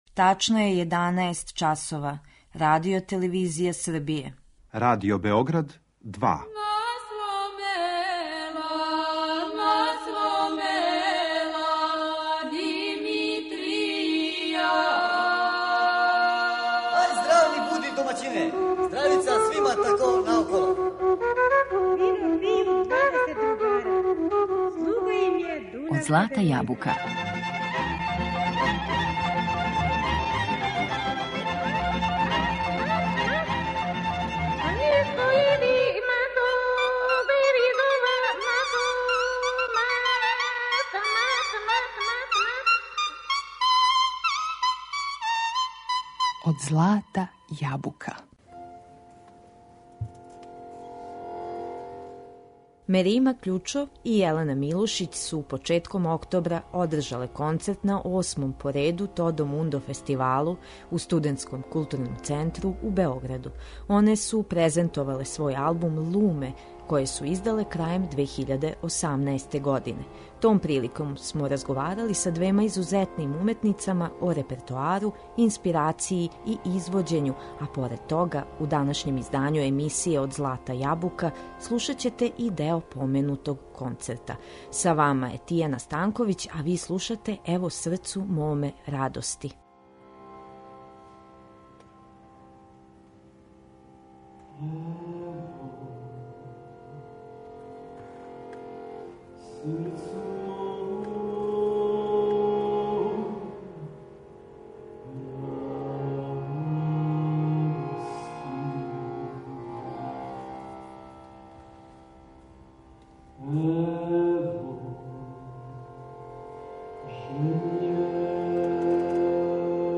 Том приликом смо разговарали са двема изузетним уметницама о репертоару, инспирацији и извођењу а поред тога, у данашњем издању емсије Од злата јабука слушаћете и део поменутог концерта.